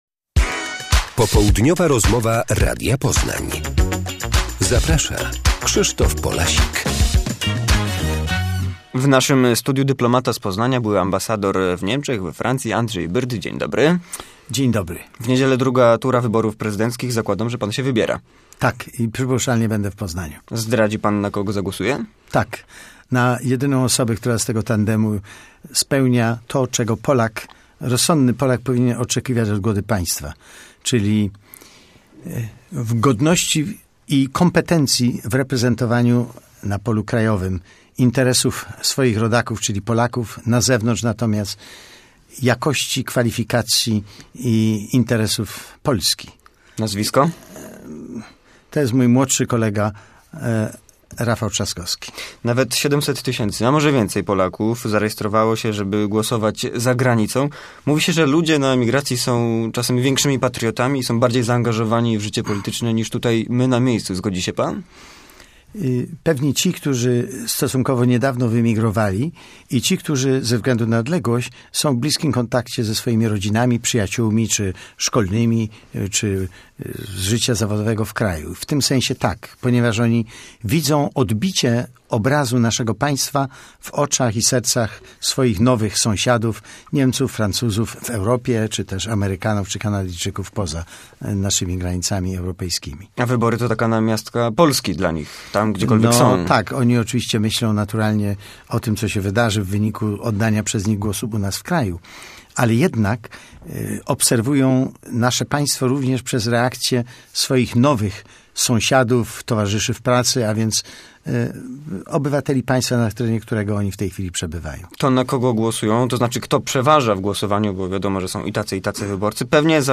Andrzej Byrt, były ambasador we Francji i Niemczech skomentował w Popołudniowej rozmowie Radia Poznań pismo amerykańskich polityków do Komisji Europejskiej.